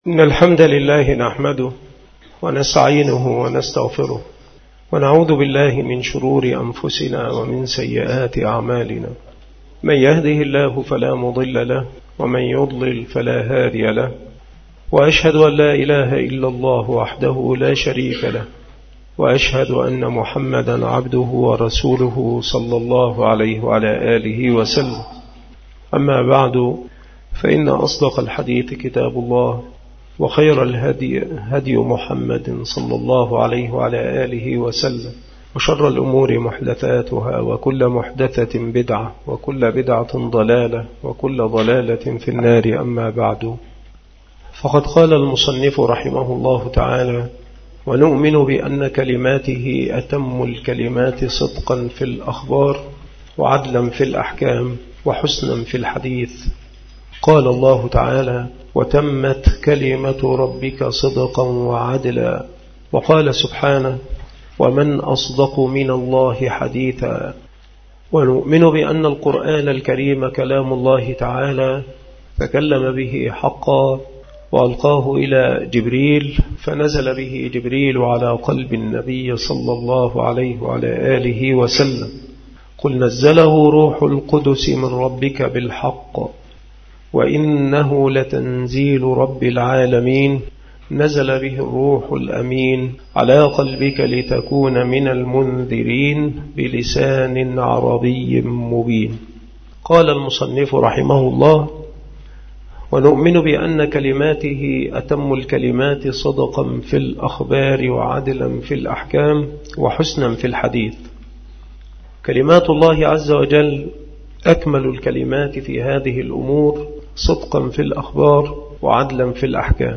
مكان إلقاء هذه المحاضرة بالمسجد الشرقي بسبك الأحد - أشمون - محافظة المنوفية – مصر